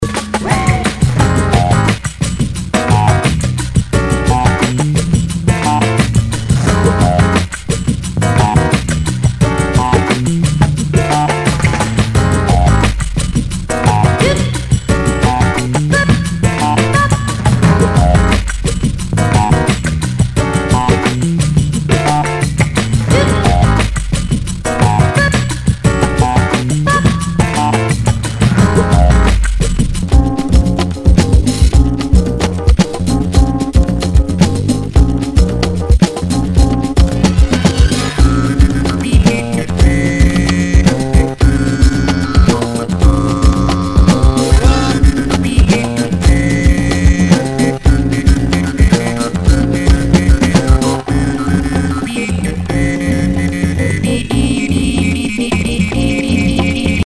Nu- Jazz/BREAK BEATS
ナイス！ダウンテンポ！